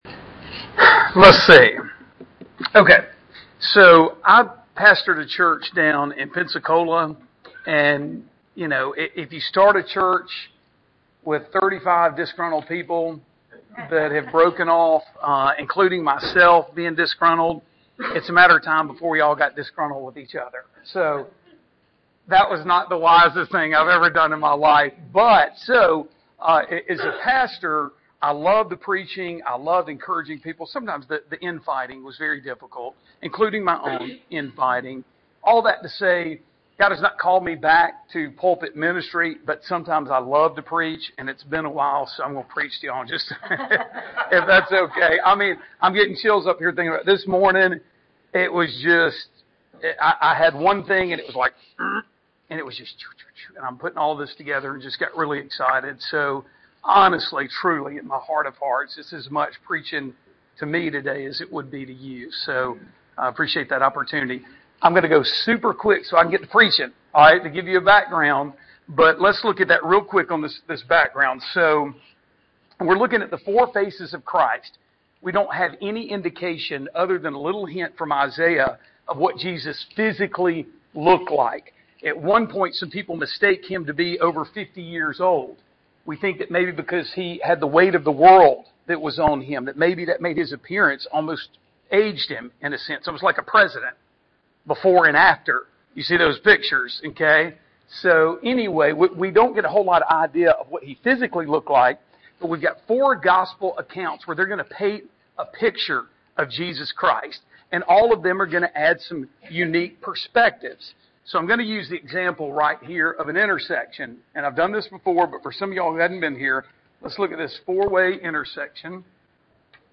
Recordings of Teaching from the Discovery Class of Briarwood Presbyterian Church in Birmingham Alabama